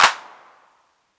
Index of /90_sSampleCDs/Classic_Chicago_House/Drum kits/kit02
cch_04_clap_mid_classic_ware.wav